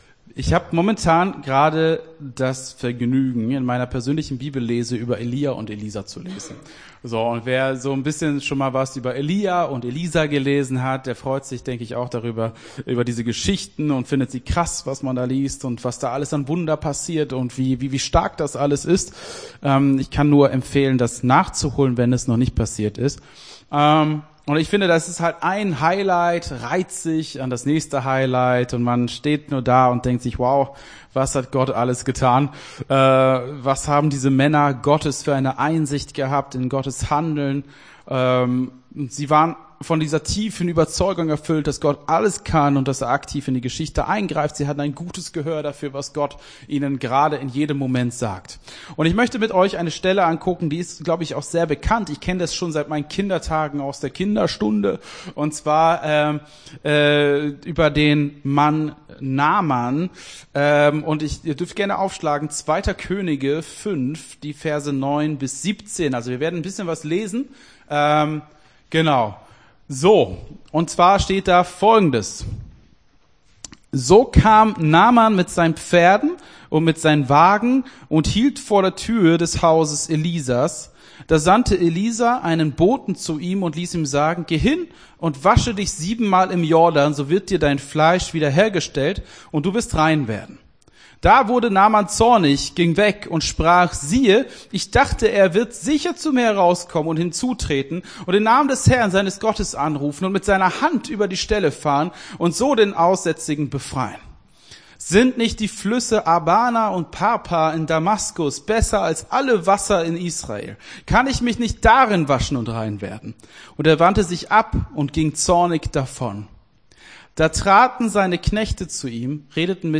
Gottesdienst 09.01.22 - FCG Hagen